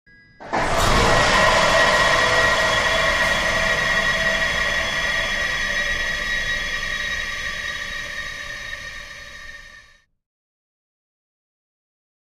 Shock Fire; Warning Beeps, Heavy Power Surge And Whoosh